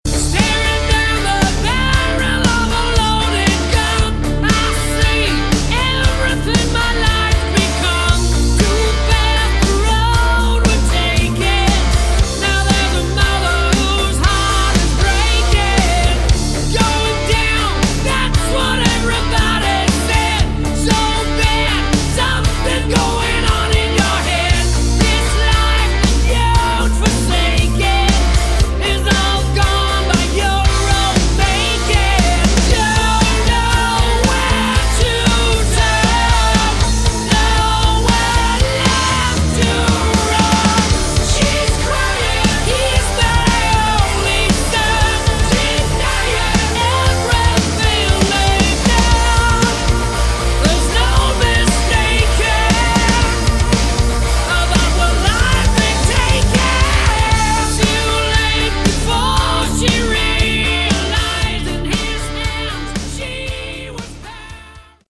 Category: Hard Rock
vocals
guitars
bass, keyboards
drums